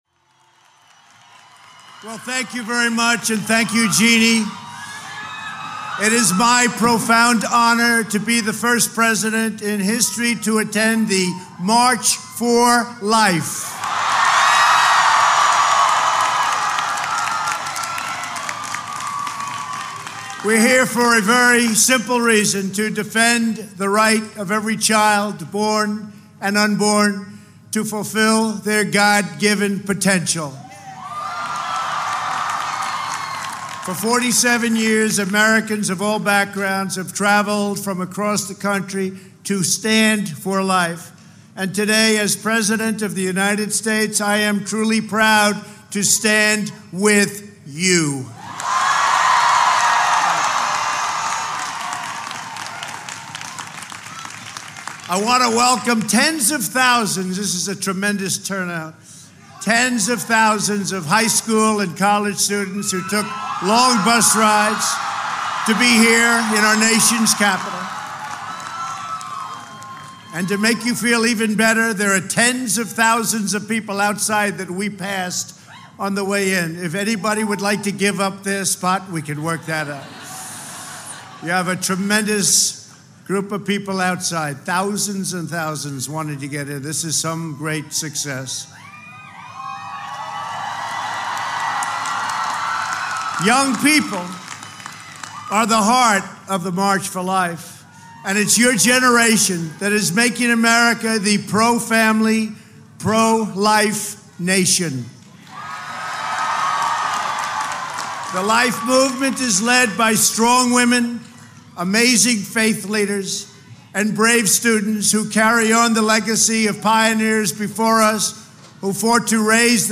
Donald J. Trump - 49th Annual March for Life Address (text-audio-video)